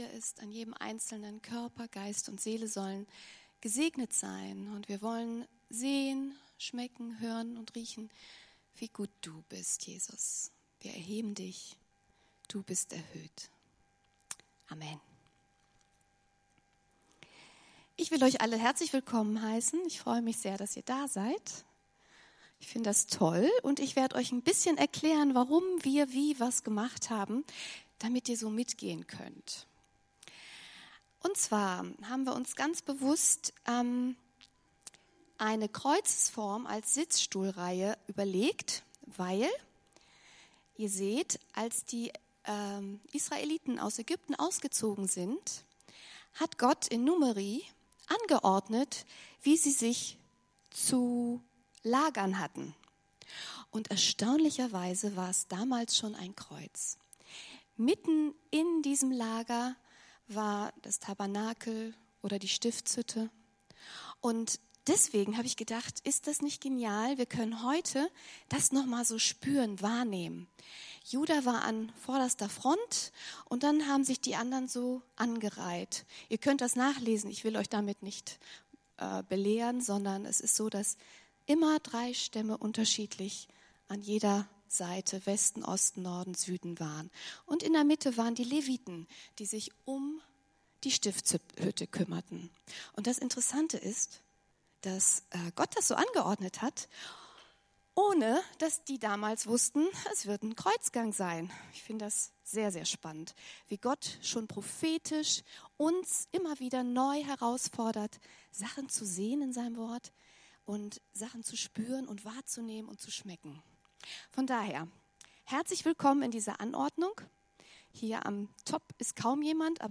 Karfreitagsgottesdienst